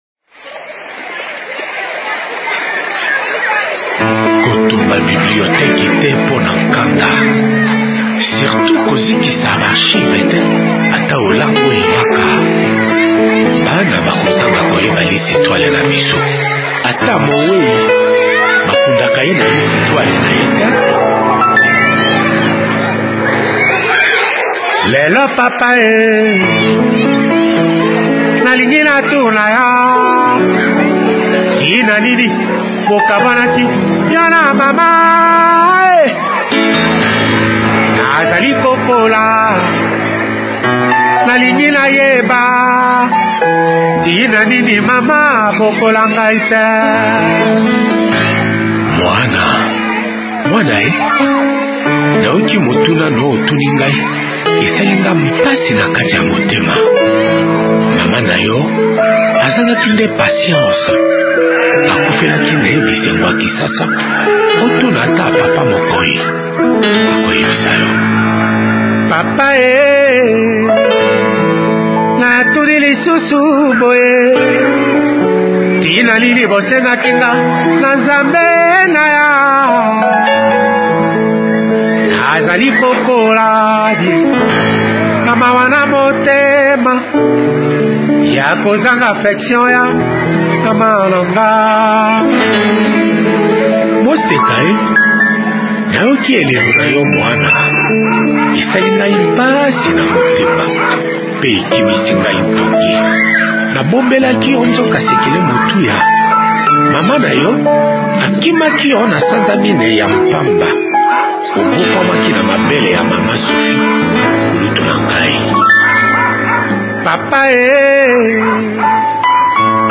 voix mélodieuse
notes du piano harmonieusement orchestrées